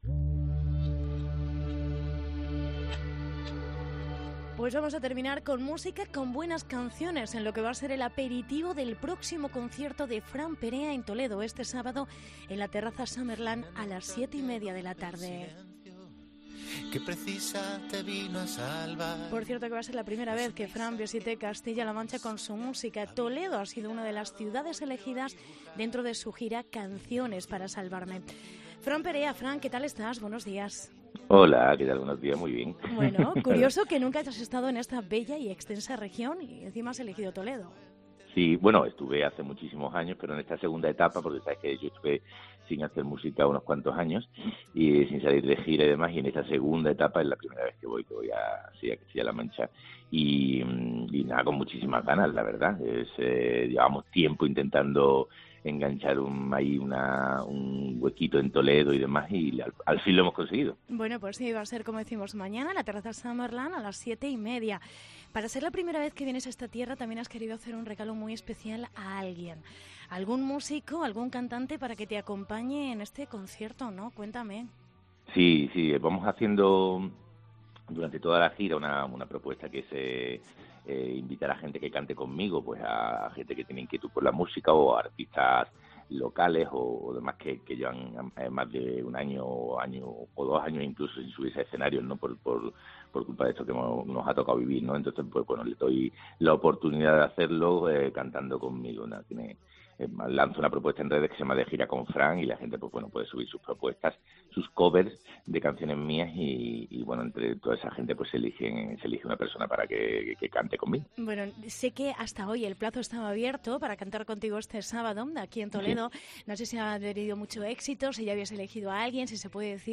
Entrevista Fran Perea